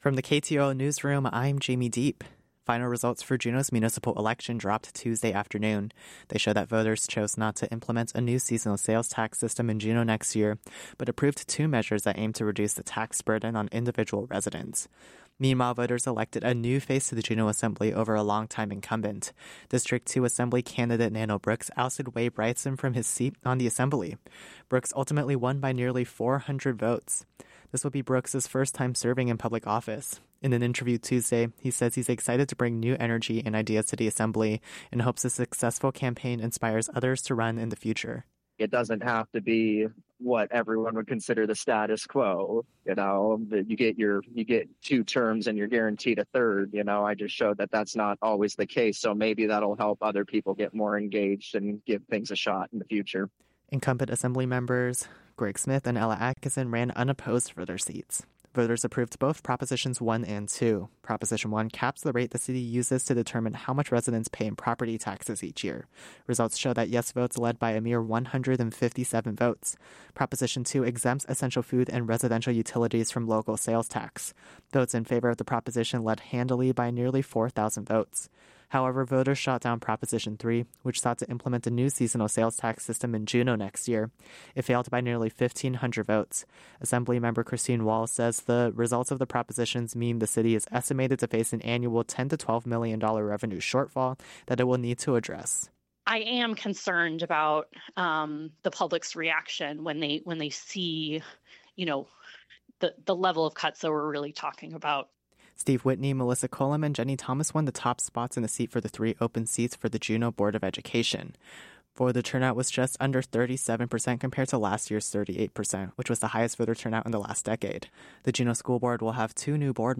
Newscast – Wednesday, Oct. 22, 2025